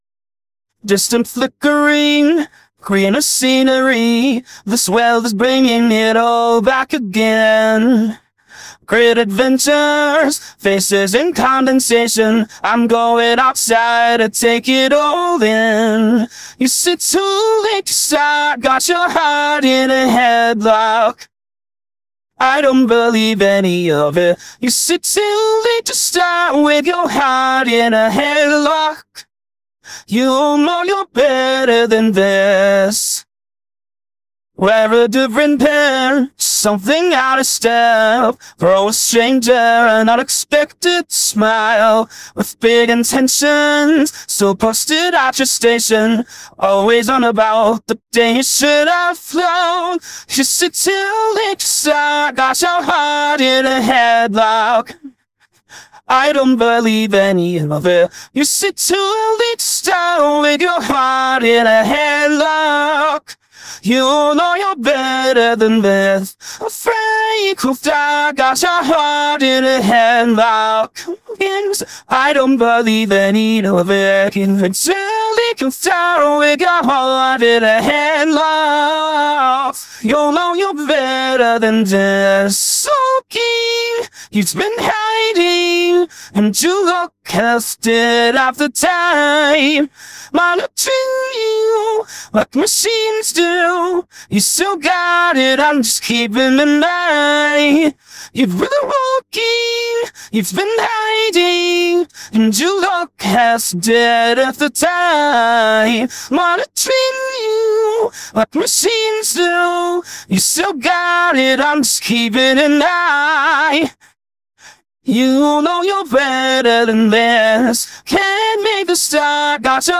Striker (Helluva Boss | Series | Singing) - (RVC V2 | RVMPE | Legacy Core Pretrain) - (160 Epochs) AI Voice Model
Striker-Singing_HEADLOCK.wav